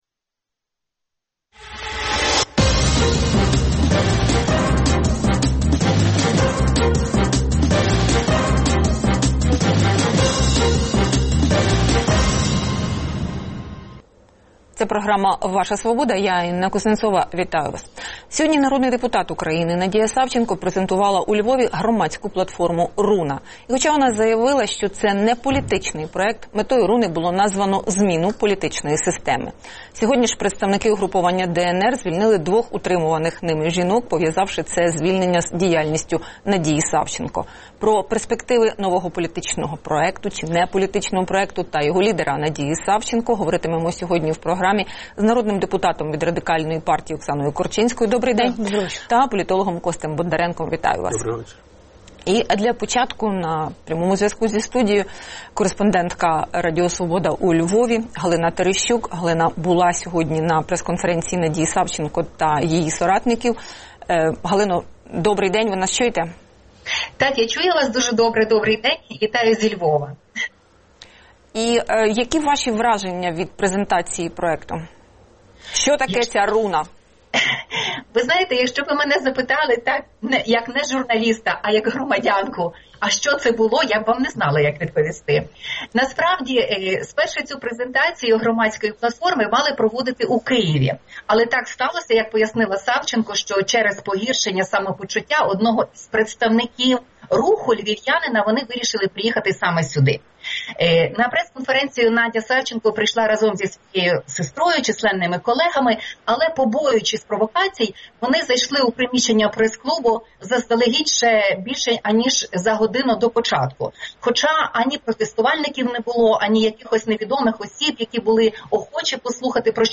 Оксана Корчинська, народний депутат України
політичний експерт.